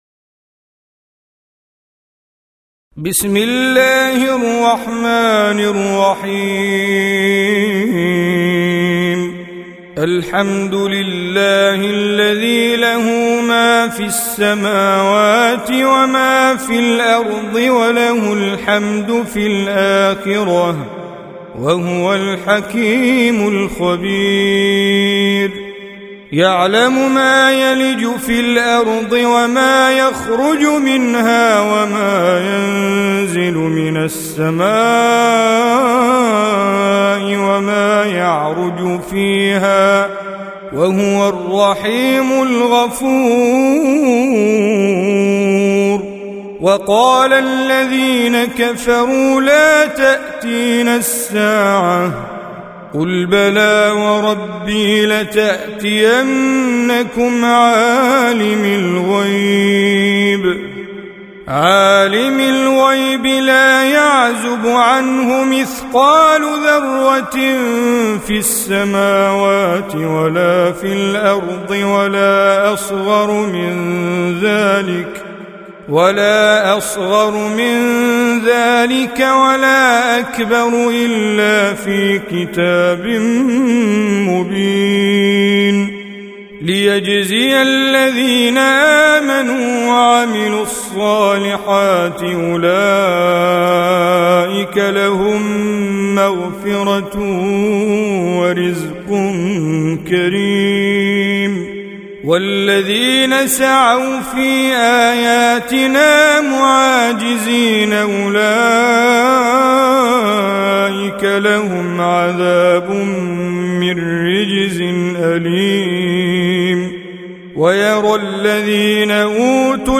34. Surah Saba' سورة سبأ Audio Quran Tajweed Recitation
Surah Repeating تكرار السورة Download Surah حمّل السورة Reciting Mujawwadah Audio for 34.